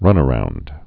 (rŭnə-round)